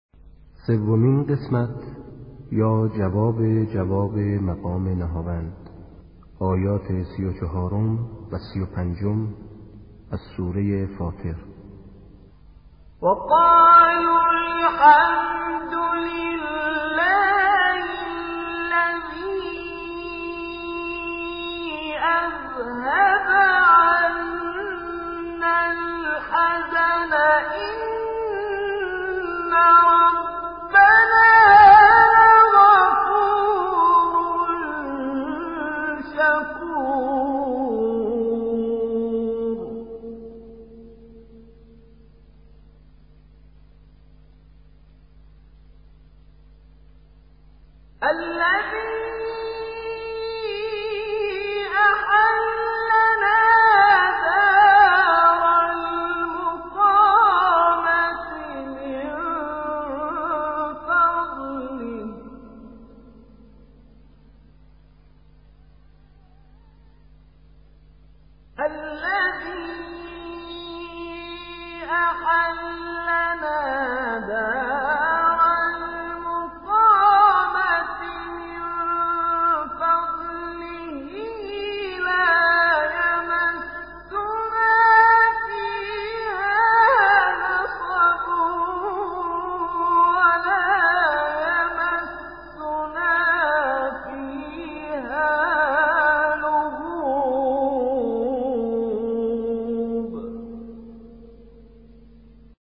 جواب جواب مقام نهاوند.mp3
• مقام, نغمه نهاوند, جواب جواب مقام نهاوند, مقام نهاوند, نغمه, جواب جواب نغمه نهاوند, نهاوند